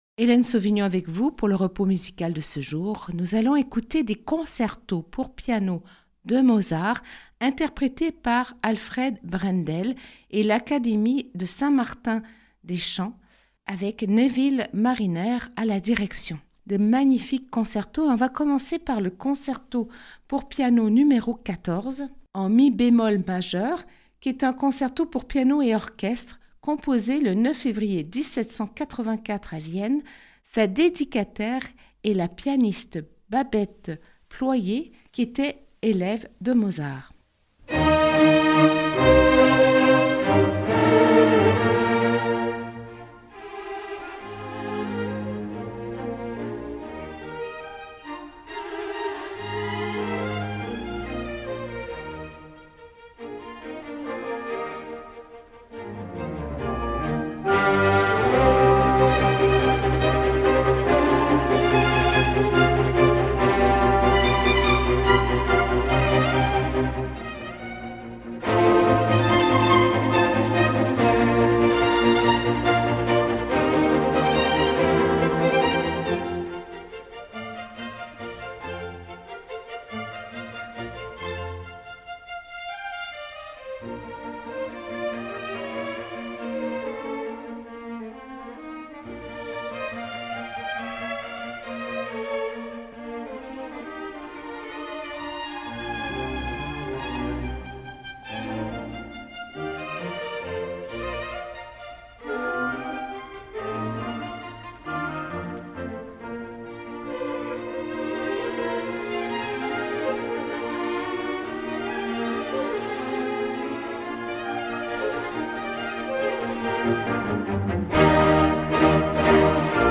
THE PIANO CONCEROS DE MOZART -K449 IN E FLAT + K450 IN B FLAT + K451 IN D